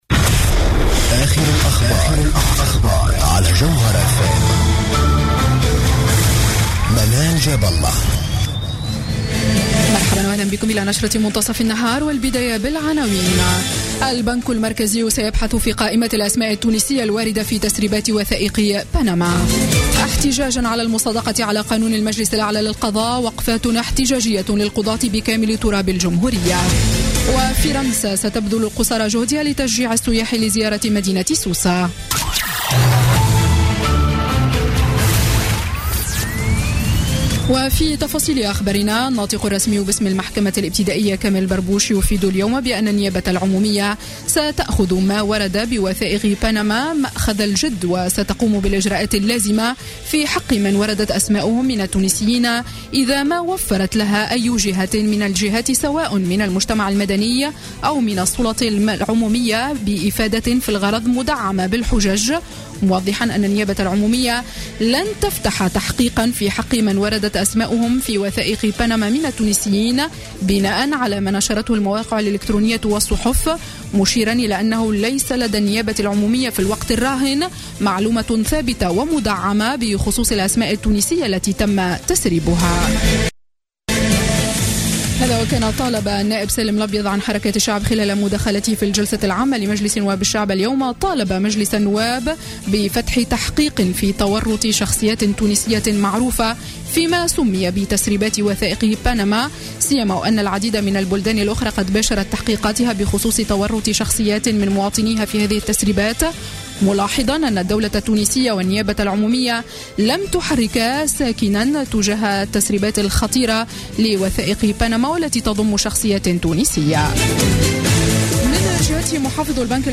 نشرة أخبار منتصف النهار ليوم الثلاثاء 5 أفريل 2016